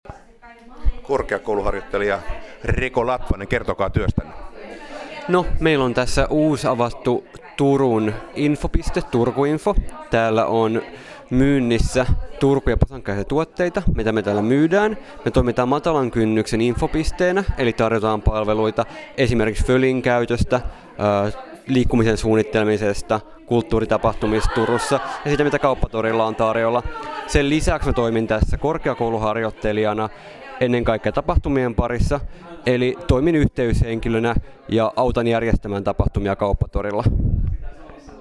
Paikka oli Forum korttelin keskusaula.